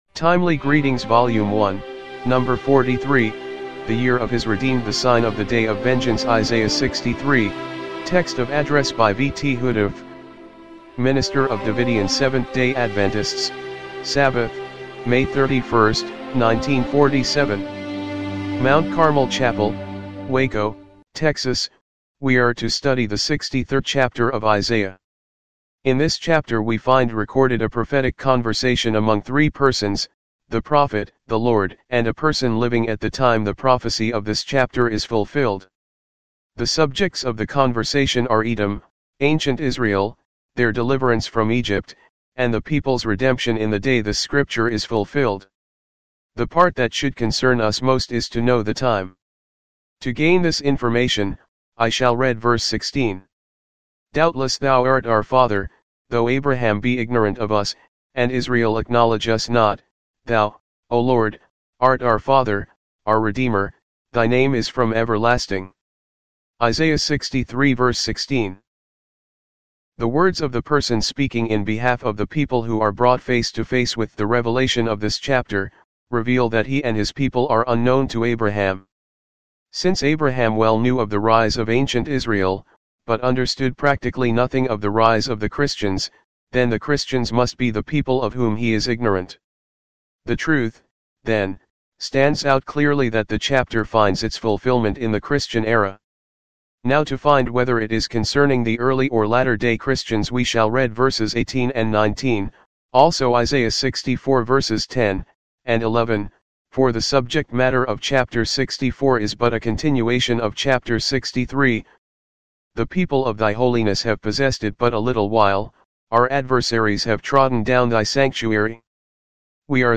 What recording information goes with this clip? timely-greetings-volume-1-no.-43-mono-mp3.mp3